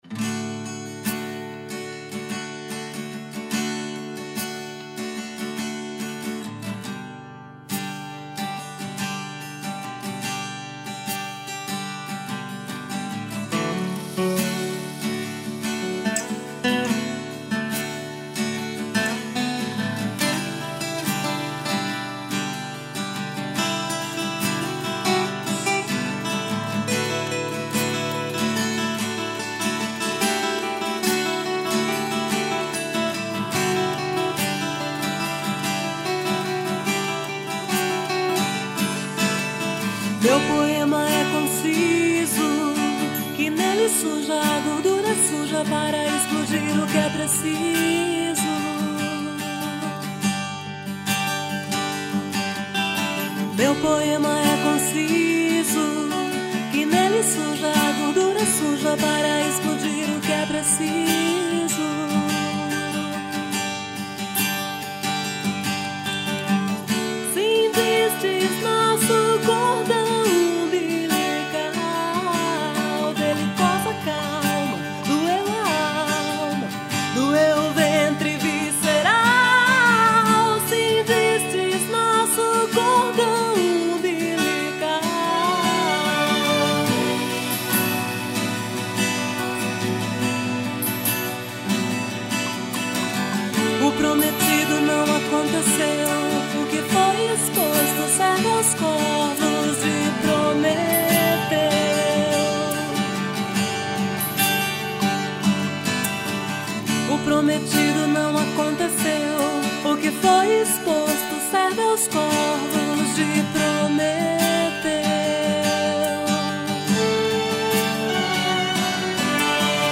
1709   06:10:00   Faixa:     Rock Nacional